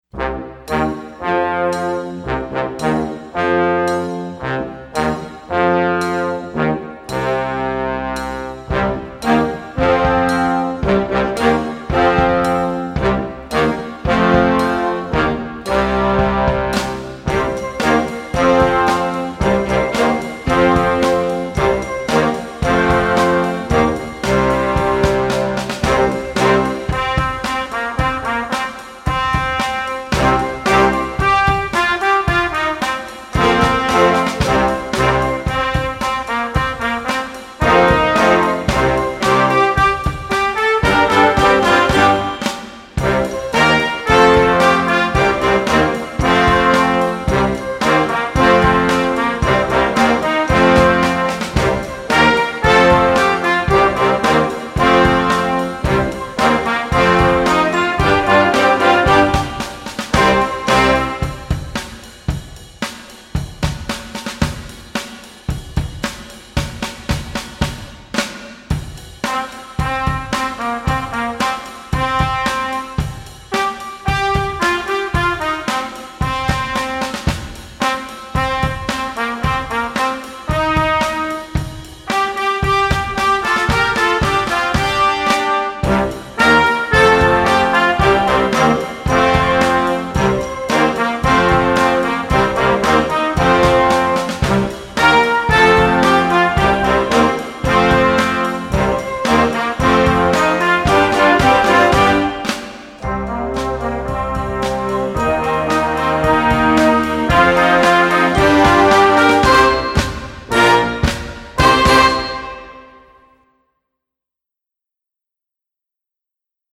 Gattung: Blasmusik für Jugendkapelle - Performer Level
Besetzung: Blasorchester